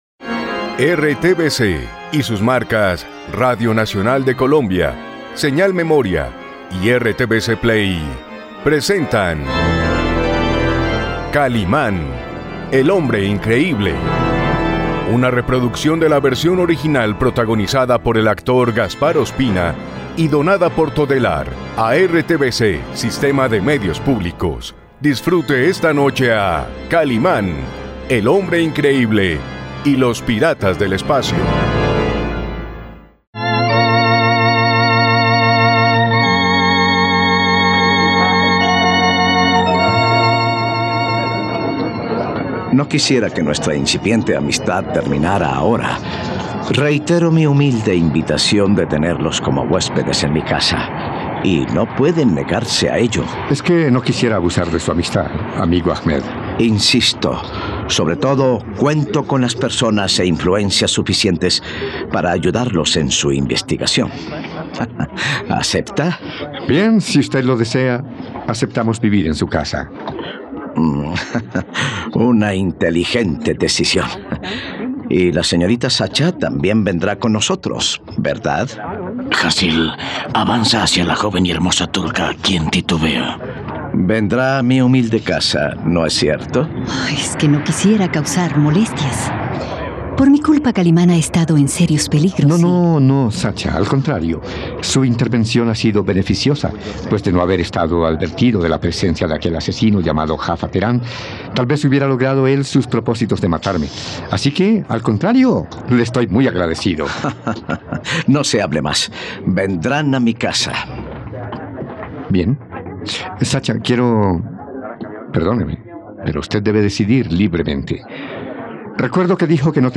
..Disfruta de una nueva aventura junto al hombre increíble, en RTVCPlay puedes disfrutar de la radionovela completa de 'Kalimán y los piratas del espacio'.